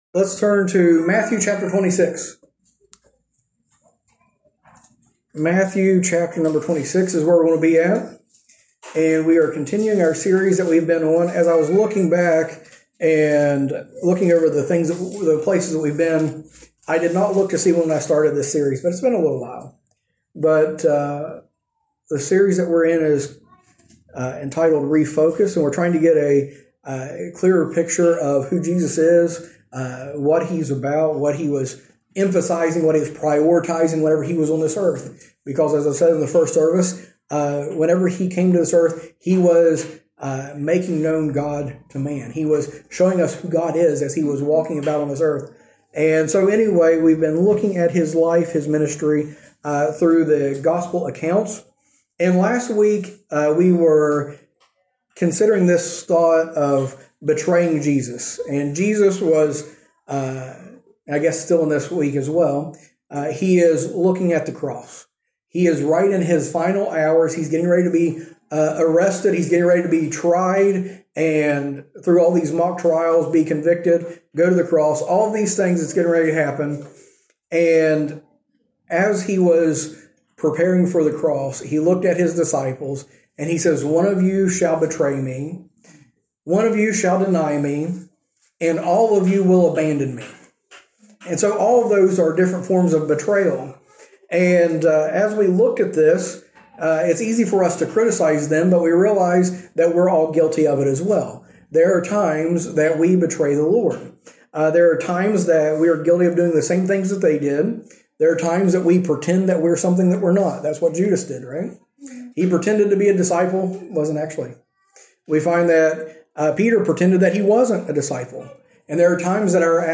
A message from the series "Refocus on Christ."